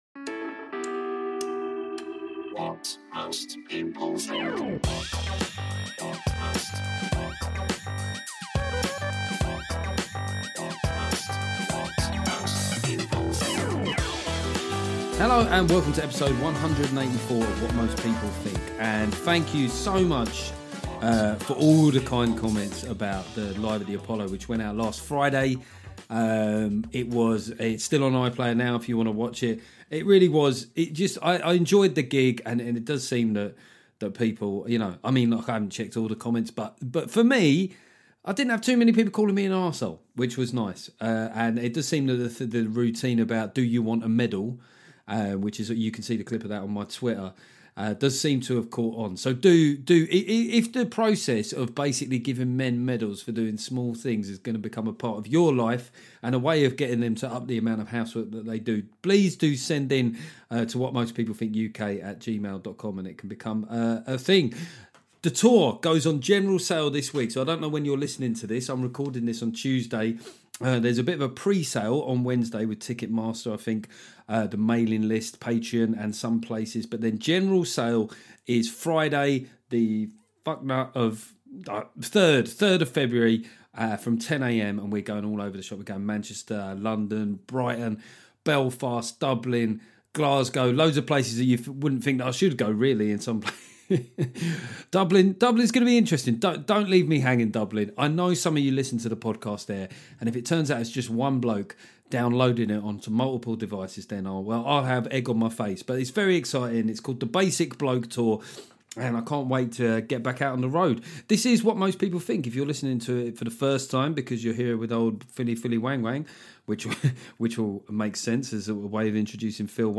After a quick rant about Sturgeon and Alastair Campbell I have a lovely chat with Phil Wang.